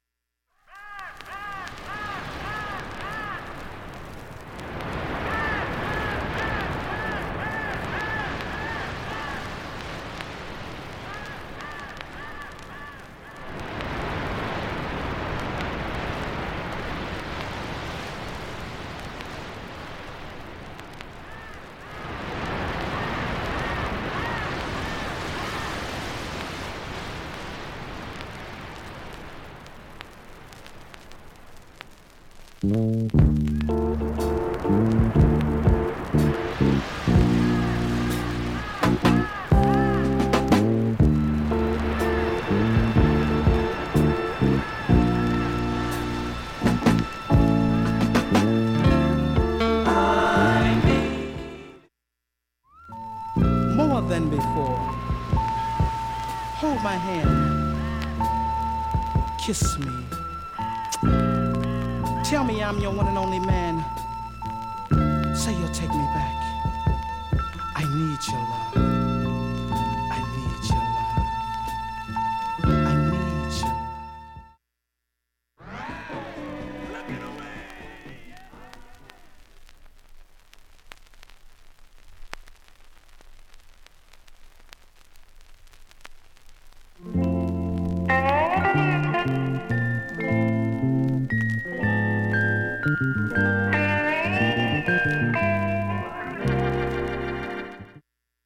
無音部等にかすかにチリ出ますが
音質良好全曲試聴済み。
(53s〜)A-1終盤にかすかなプツが９回、
エモーショナルでシネマティックソウル